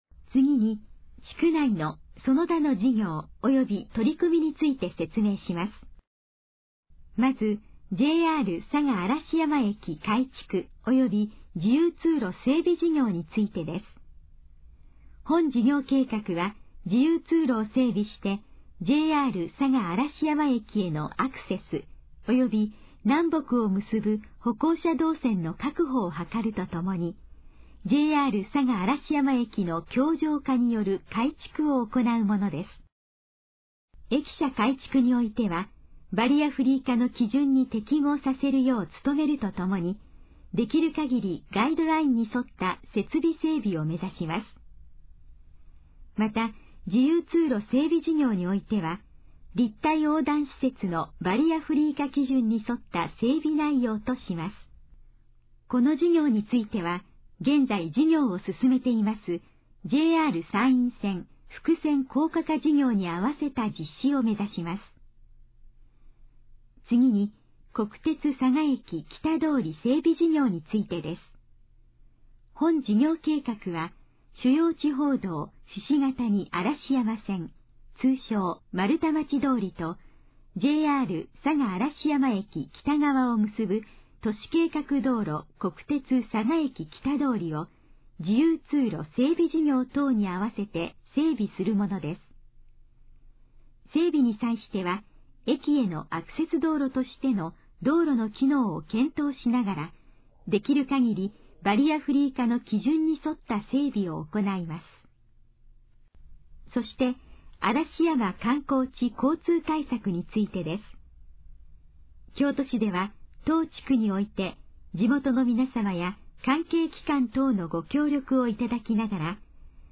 以下の項目の要約を音声で読み上げます。
ナレーション再生 約272KB